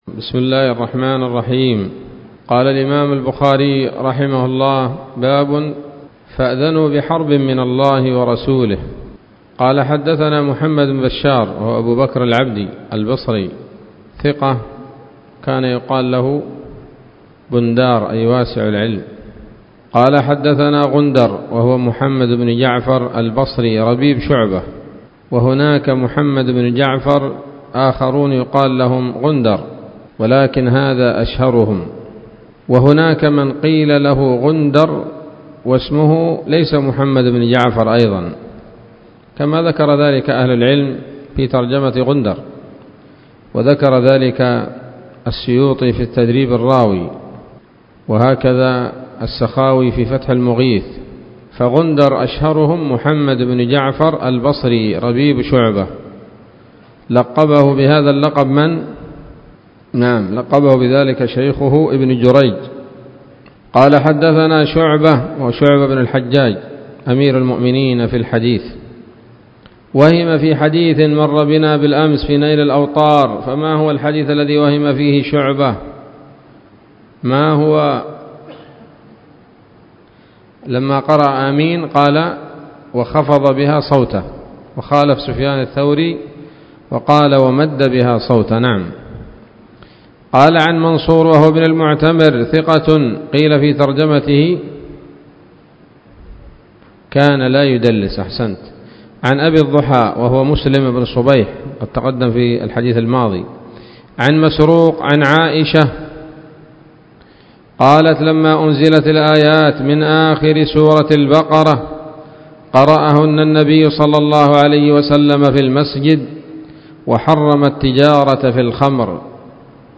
الدرس الثالث والأربعون من كتاب التفسير من صحيح الإمام البخاري